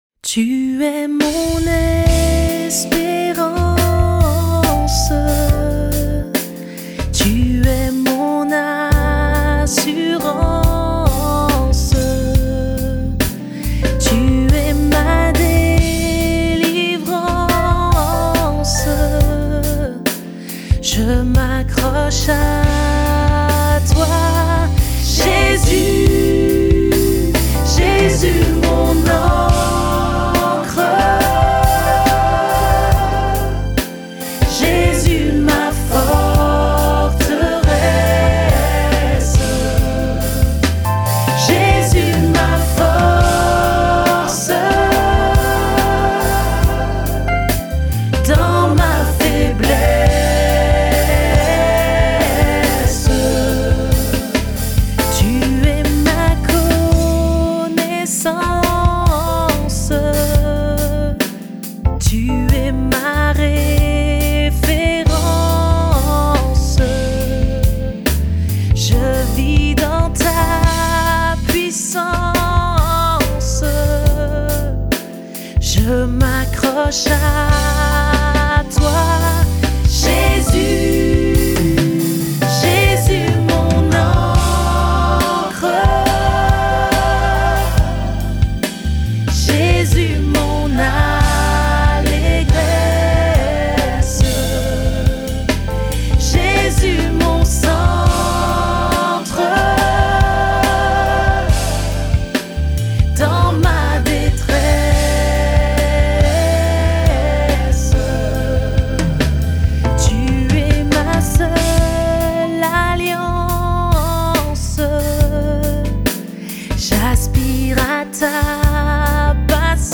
Gospel
Proclamation funky de la force tranquille